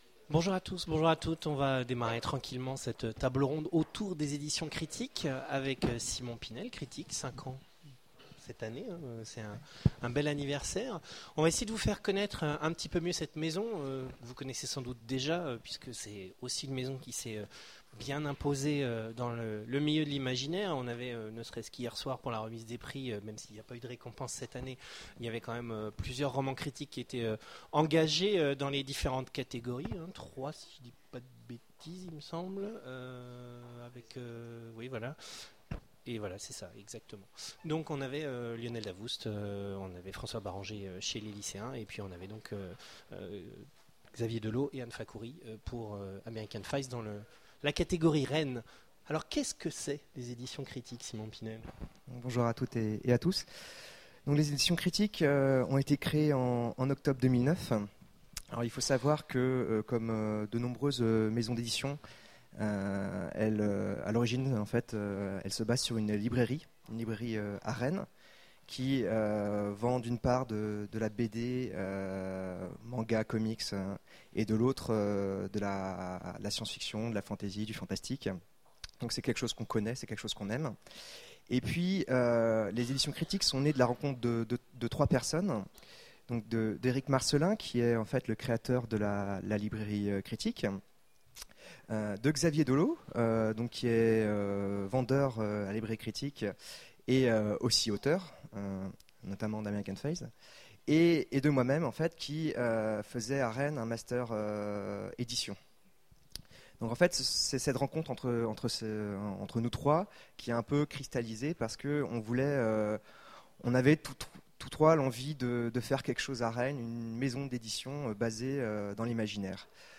Imaginales 2015 : Conférence Critic, comment ça marche ?